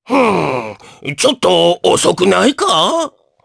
Oddy-Vox_Skill2_jp.wav